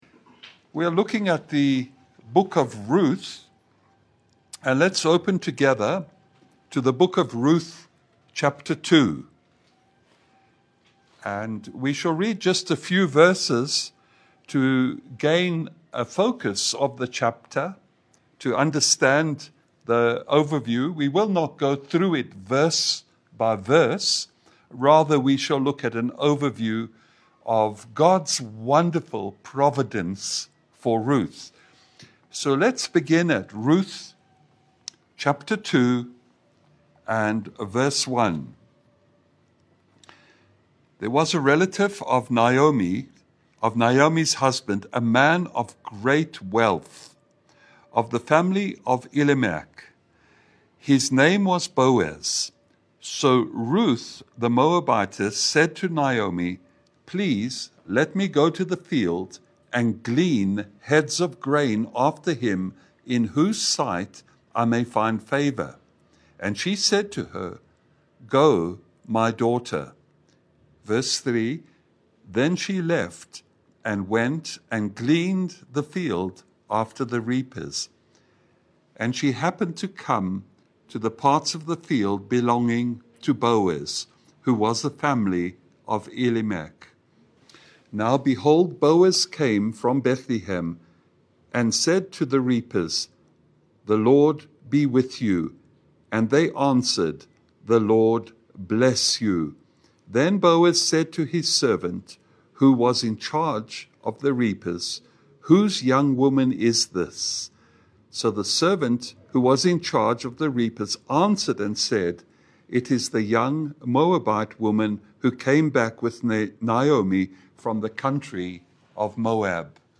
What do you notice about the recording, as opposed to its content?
Maxoil hotel Nanyuki Town